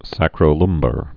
(săkrō-lŭmbər, -bär, sākrō-)